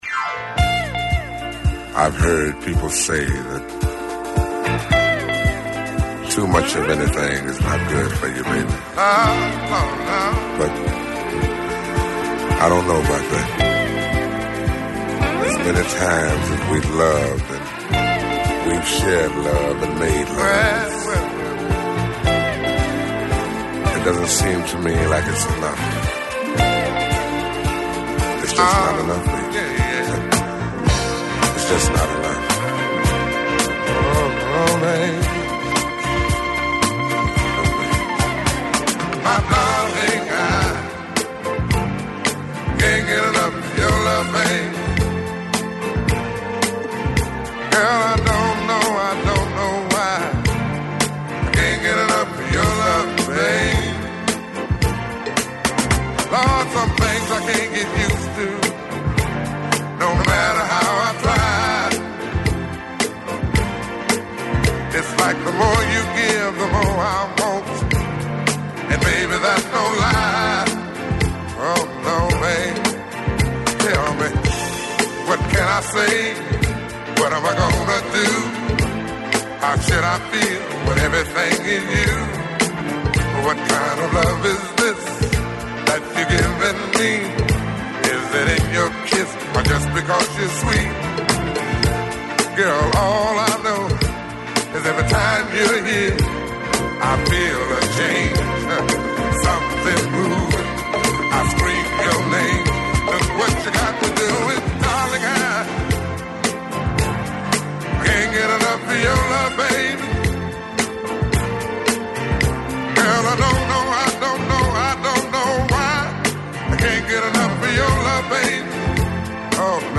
Ακούστε την εκπομπή του Νίκου Χατζηνικολάου στον RealFm 97,8, την Πέμπτη 19 Σεπτεμβρίου 2024.